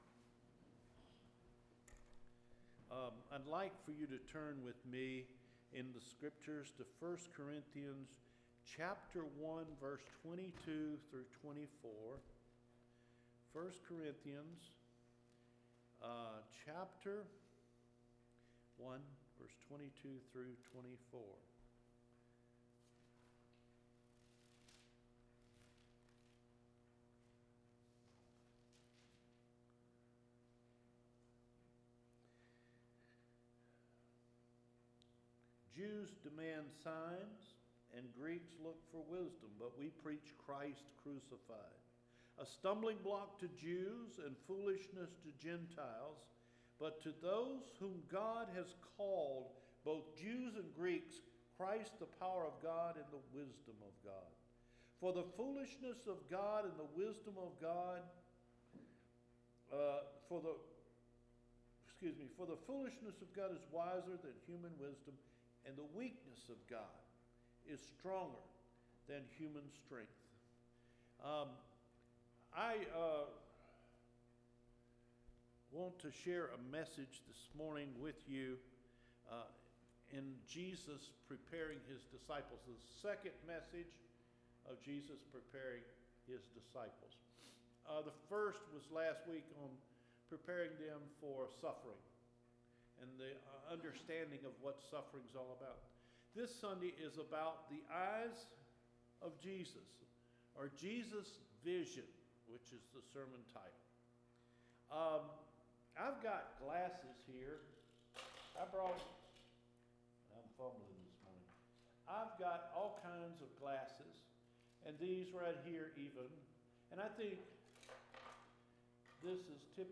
MARCH 14 SERMON – JESUS VISION